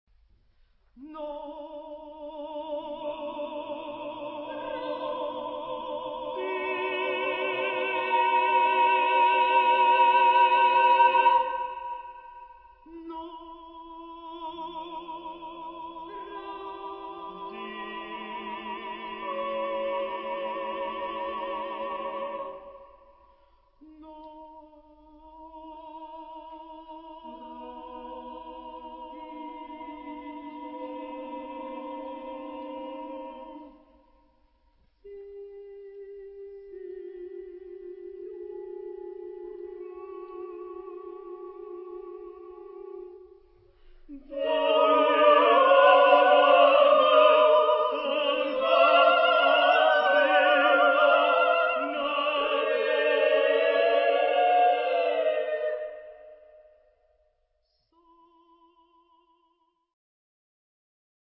Tipo de formación coral: SSATB  (5 voces Coro mixto )